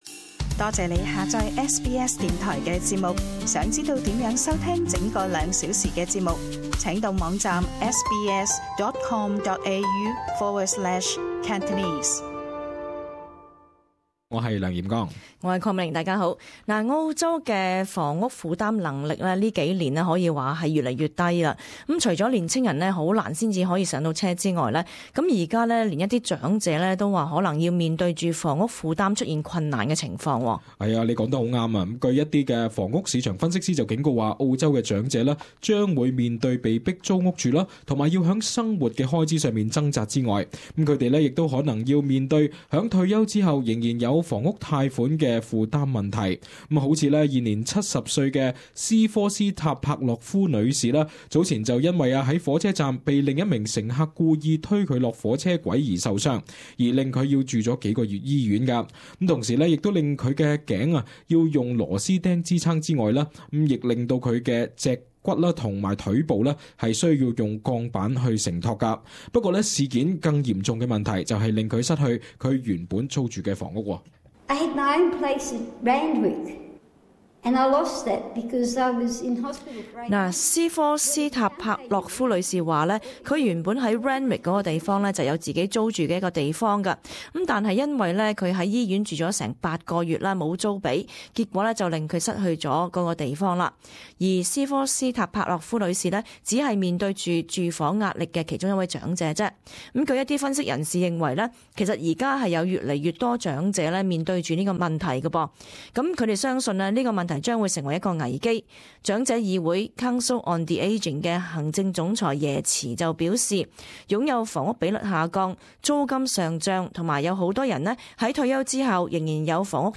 【時事報導】 澳洲長者面對住屋及房貸問題嚴重